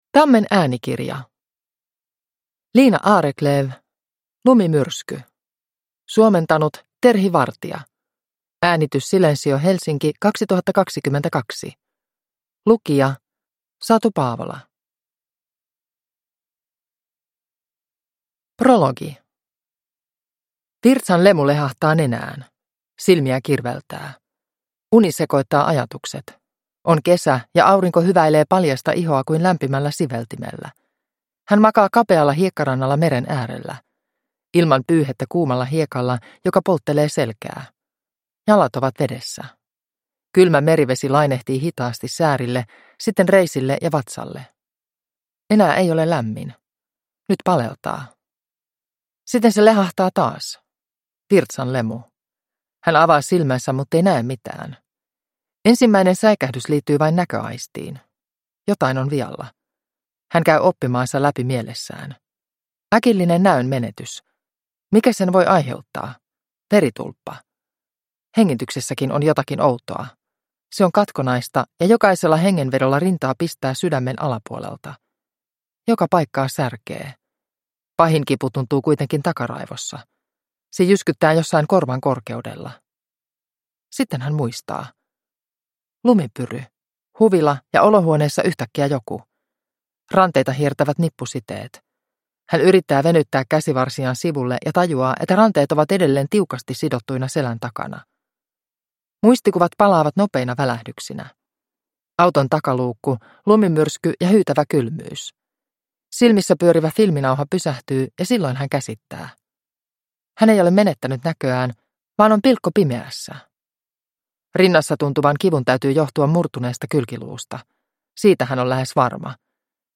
Lumimyrsky – Ljudbok – Laddas ner